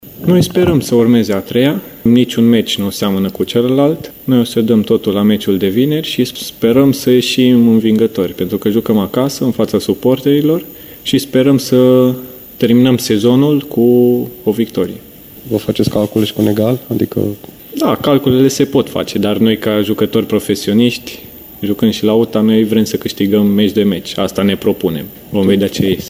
Despre buletinul medical a vorbit antrenorul Mircea Rednic: